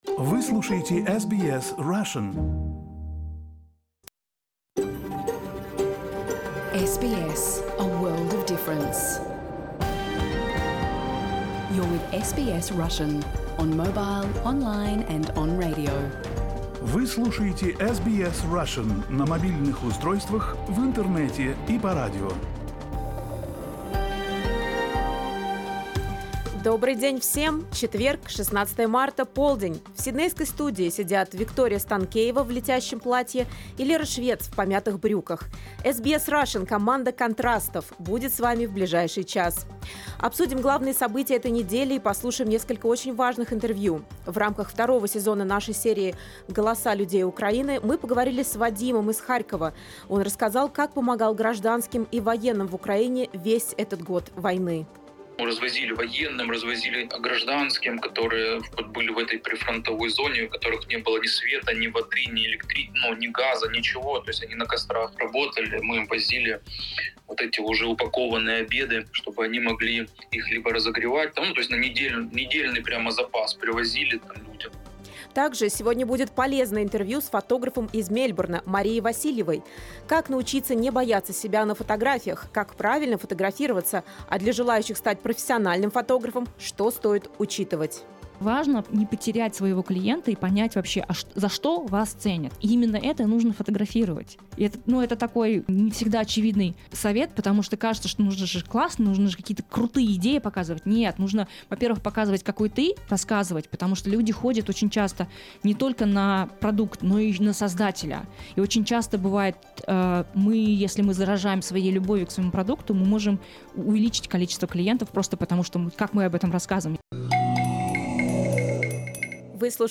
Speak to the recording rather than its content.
You can listen to SBS Russian program live on the radio, on our website and on the SBS Radio app.